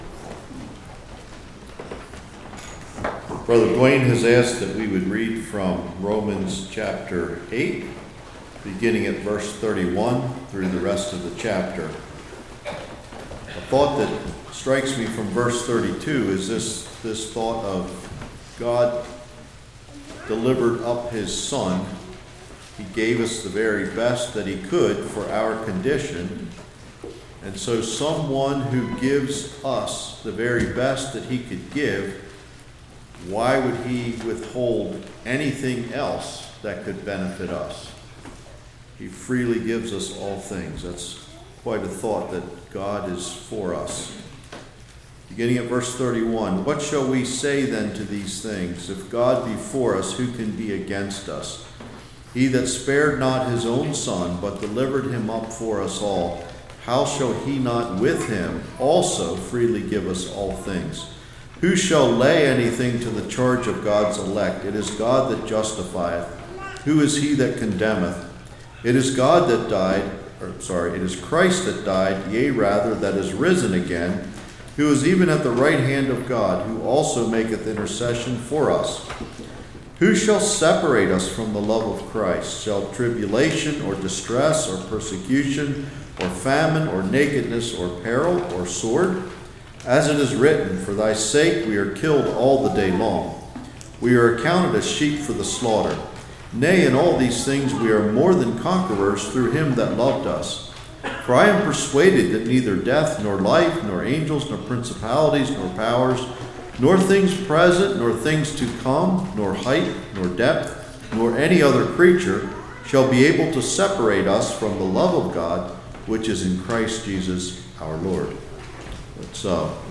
Passage: Romans 8:31-39 Service Type: Morning God’s love is great God is love Does God Love Everyone?